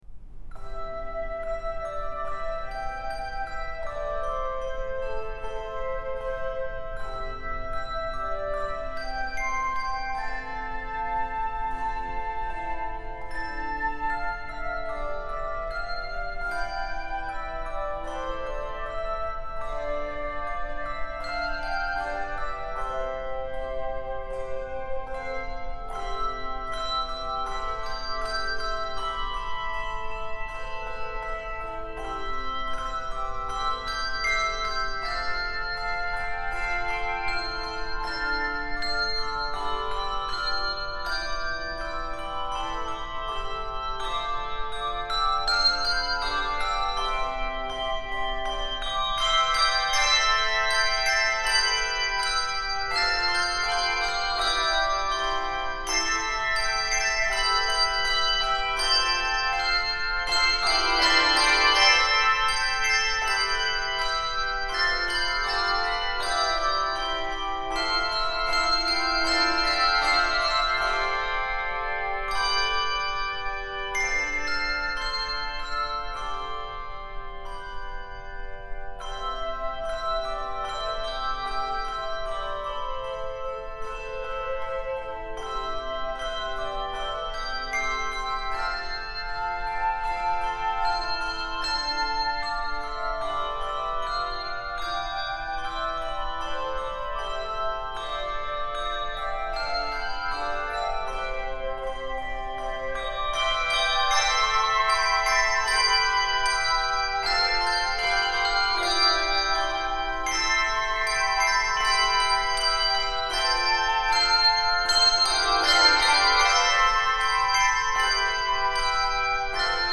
Voicing: Handbells 3 Octave